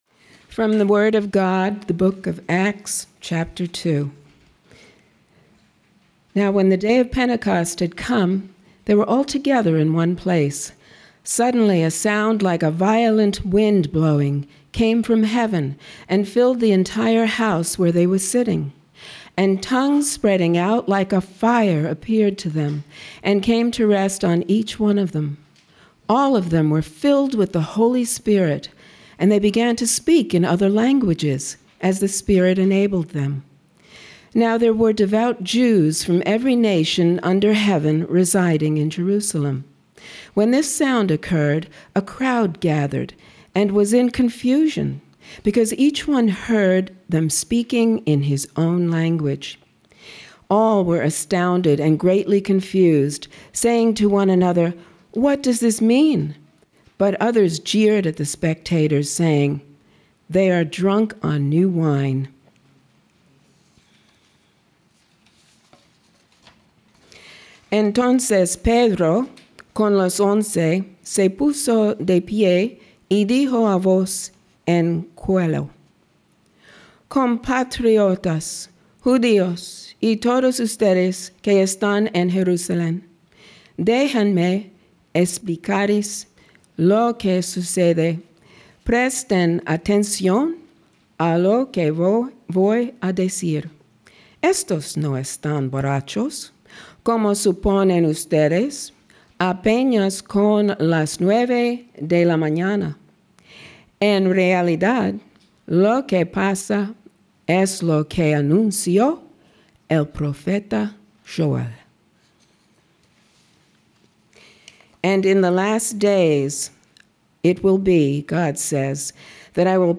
Pentecost Sermon Audio June 8, 2025